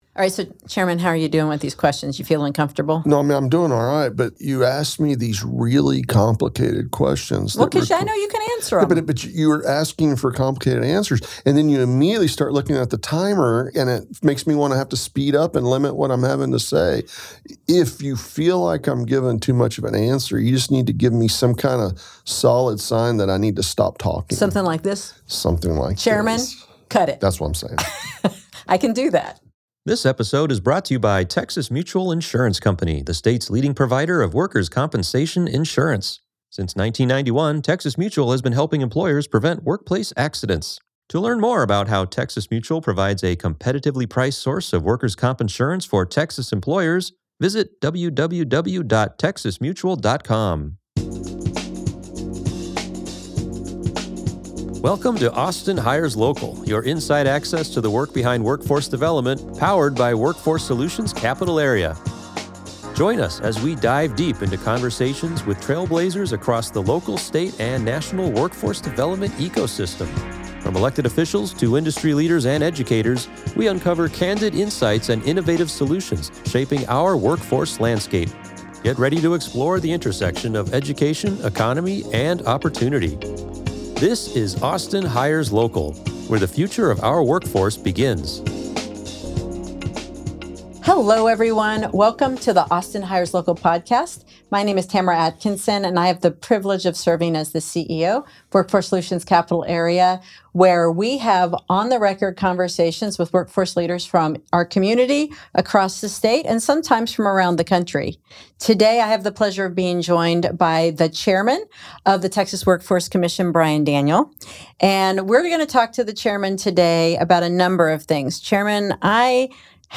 as she interviews Chairman Bryan Daniel of the Texas Workforce Commission in this revealing conversation about Texas's workforce landscape.